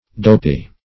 Dopey \Dop"ey\, Dopy \Dop"y\, a.